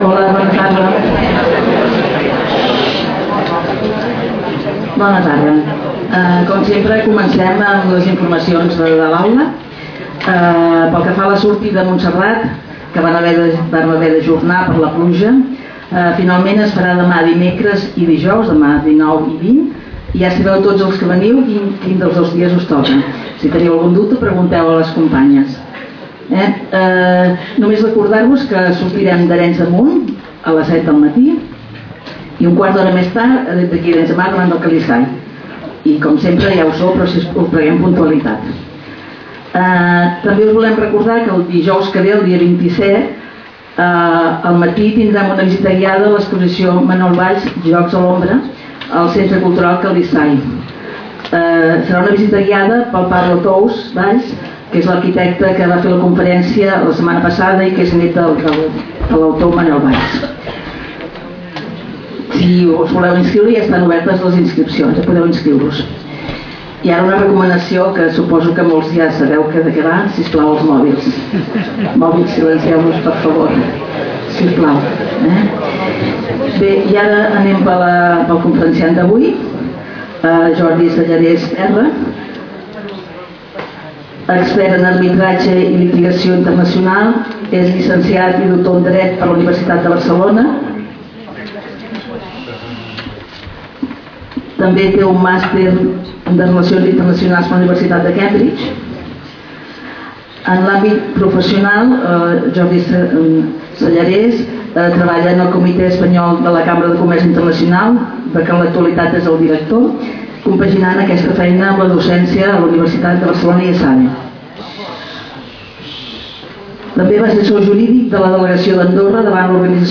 Lloc: Casal de Joventut Seràfica
Conferències